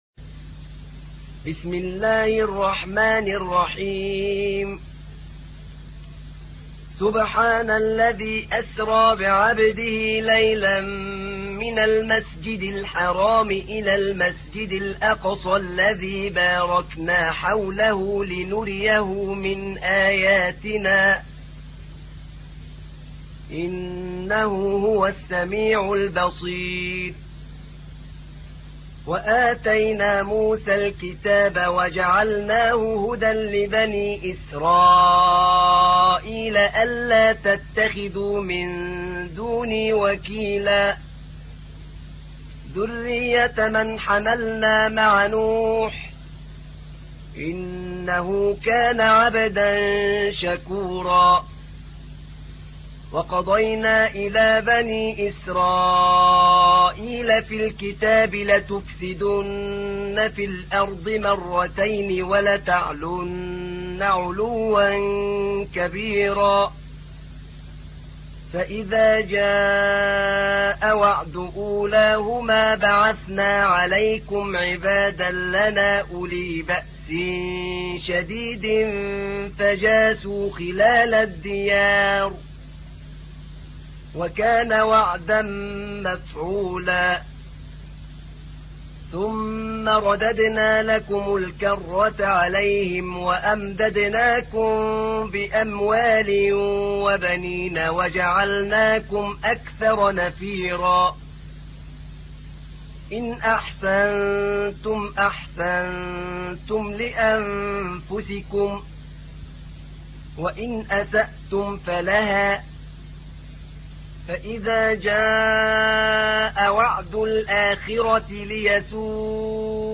تحميل : 17. سورة الإسراء / القارئ أحمد نعينع / القرآن الكريم / موقع يا حسين